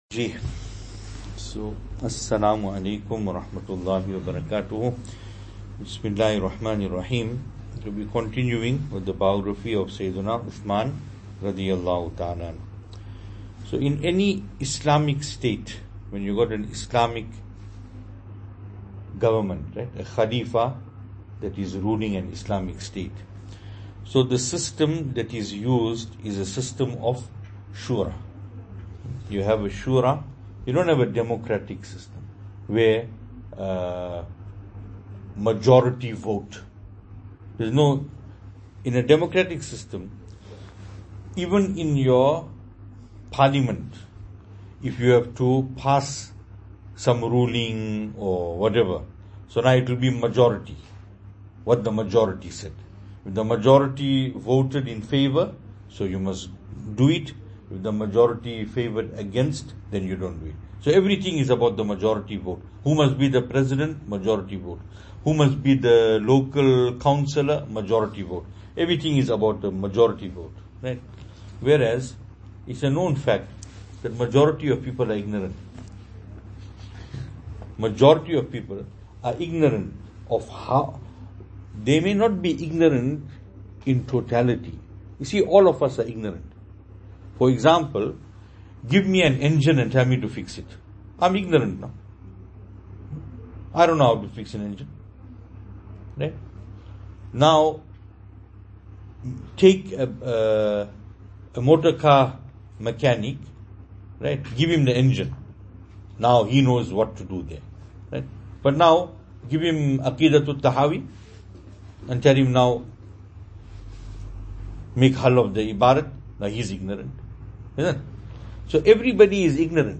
Venue: Albert Falls , Madressa Isha'atul Haq
Service Type: Majlis